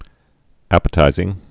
(ăpĭ-tīzĭng)